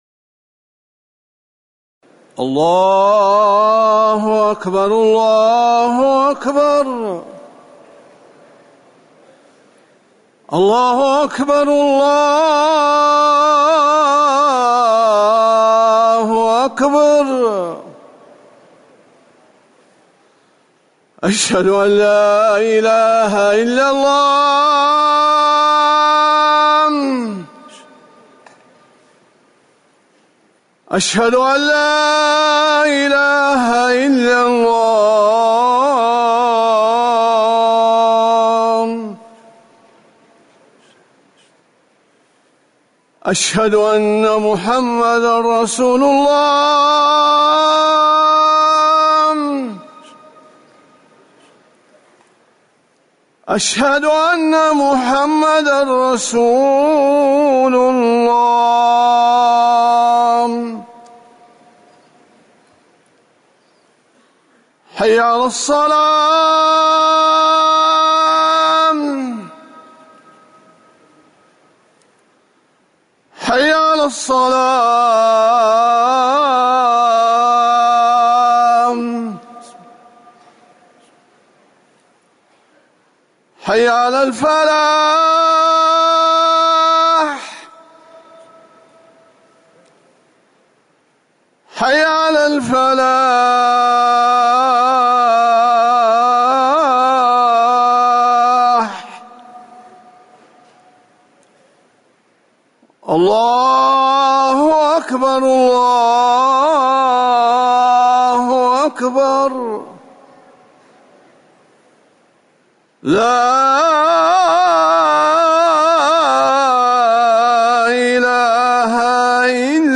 أذان المغرب - الموقع الرسمي لرئاسة الشؤون الدينية بالمسجد النبوي والمسجد الحرام
تاريخ النشر ٢٩ صفر ١٤٤١ هـ المكان: المسجد النبوي الشيخ